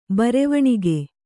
♪ barevaṇige